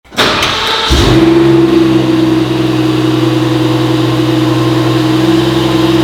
Kaltstart_Stage3_M760li.mp3